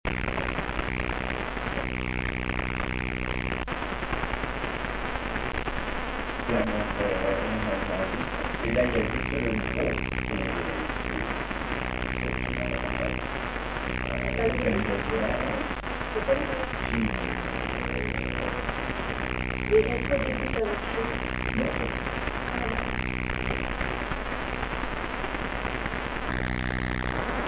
Quand je n'enclenche que le micro, j'obtiens des grésillements horribles, et un son extrêmement faible:
format MP3 : les voix sont inaudibles